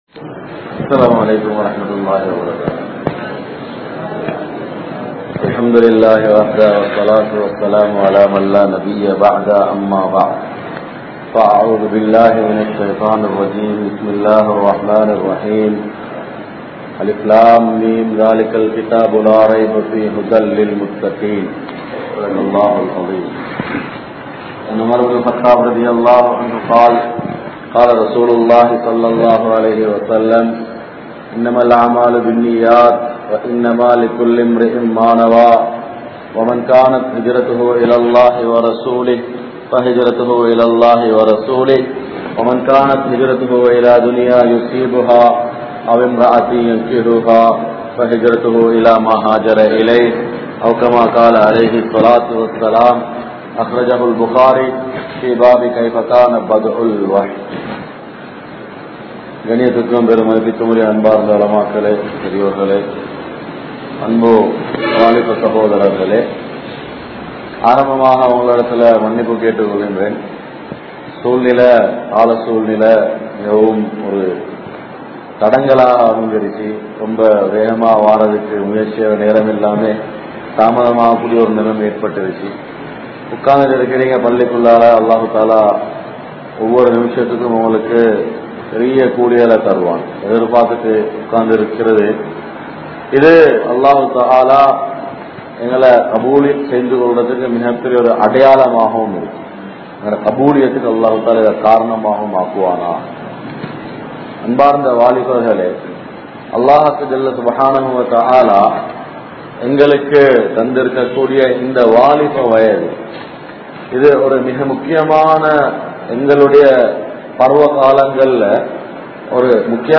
Naveena Ulahamum Vaalifarhalum (நவீன உலகமும் வாலிபர்களும்) | Audio Bayans | All Ceylon Muslim Youth Community | Addalaichenai